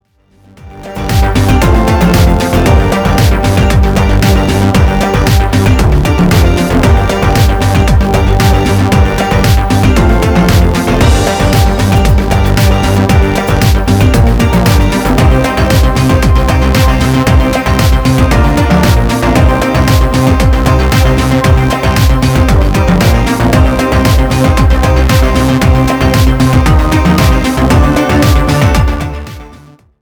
FUNK  (3.16)